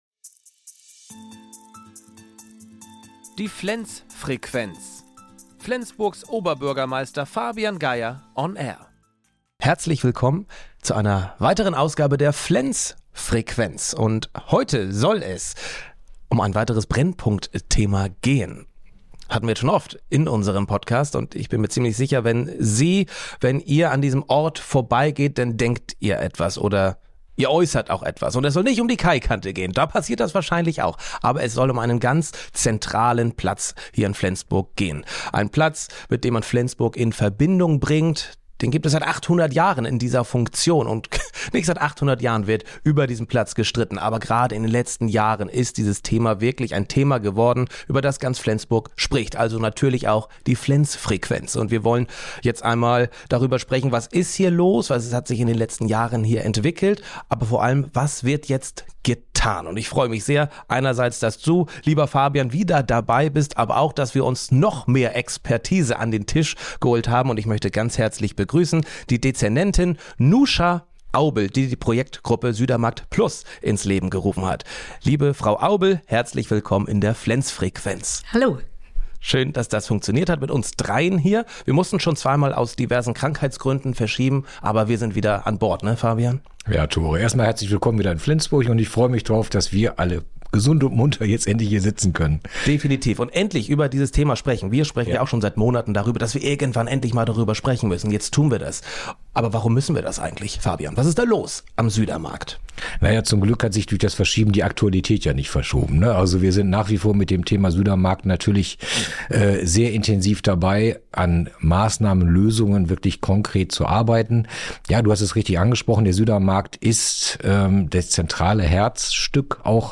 Der viel-frequentierte Südermarkt bietet aktuell hohes Konfliktpotenzial - OB Fabian Geyer und die zuständige Dezernentin Noosha Aubel stellen in Folge 5 die kleinen und großen Lösungsansätzen vor, vom Ordnungsdienst bis zu "Little Homes".